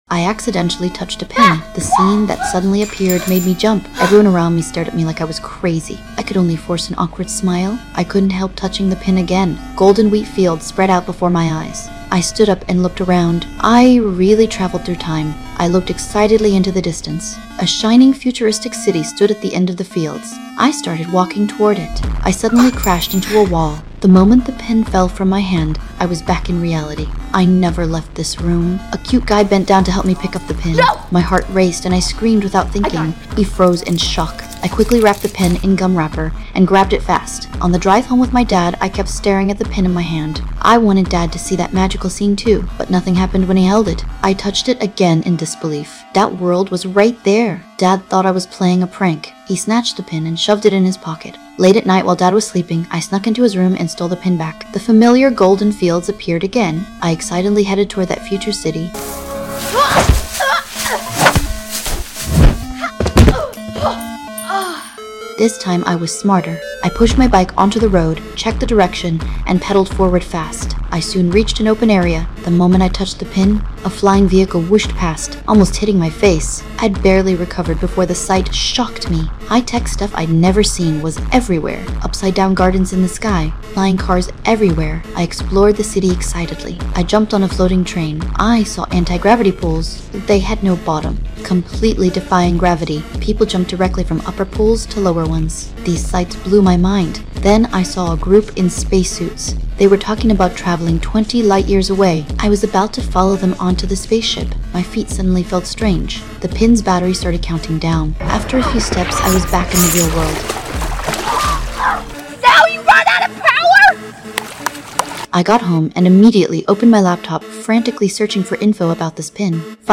Tell the story of “ Tomorrowland ” from Casey’s perspective.(AIGC Audio&Orignal content)